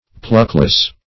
Pluckless \Pluck"less\, a.